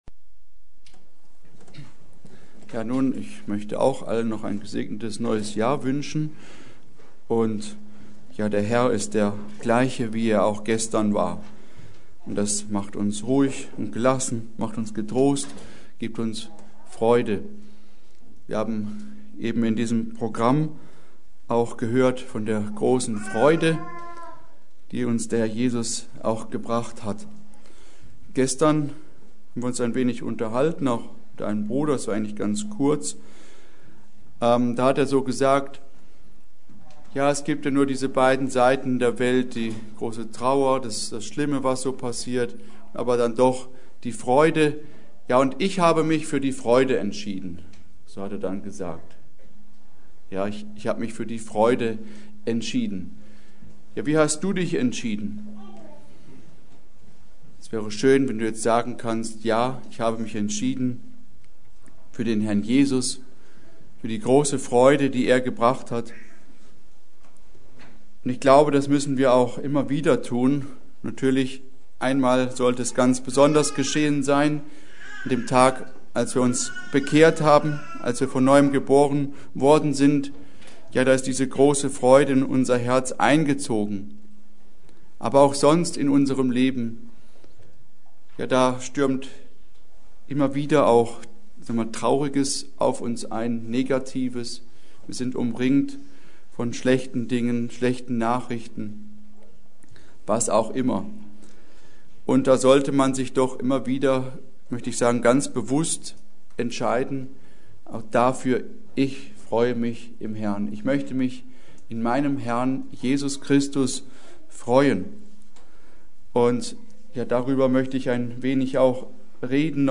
Predigt: Seid allezeit fröhlich; darum wacht!